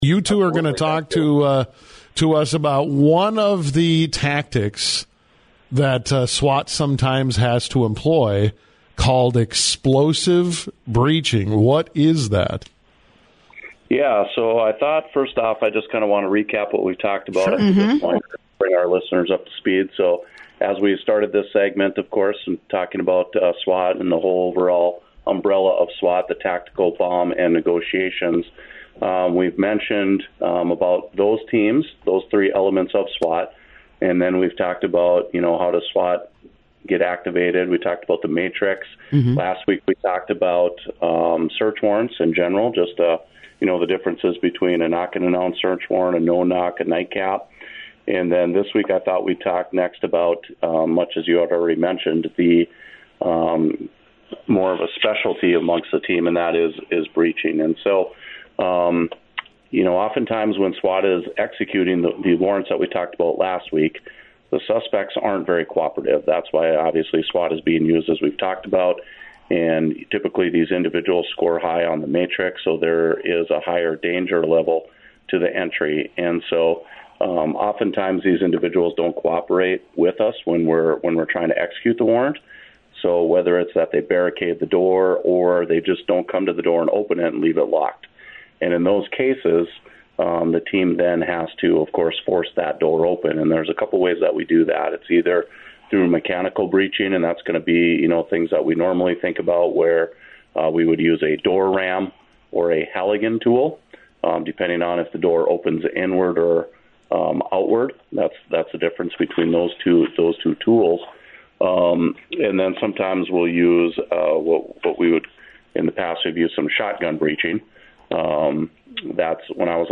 on WDAY Radio’s “The Coffee Club”.